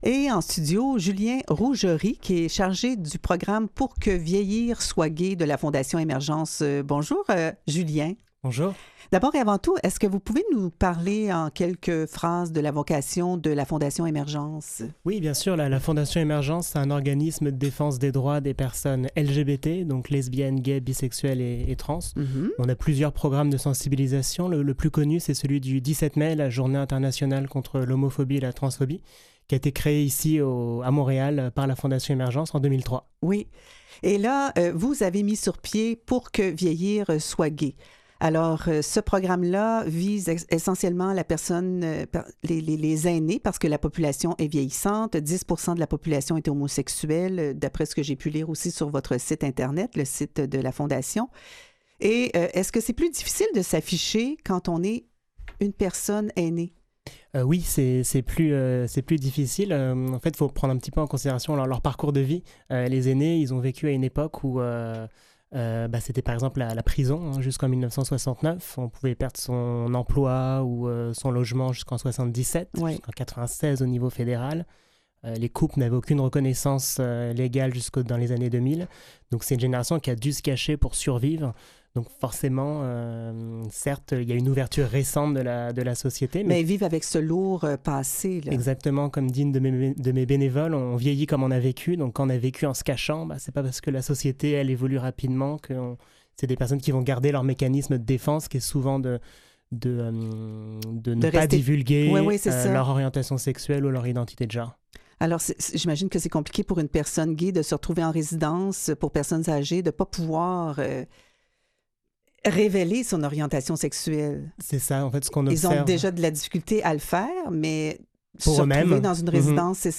Revue de presse et entrevues - Canal M, la voix de l'inclusion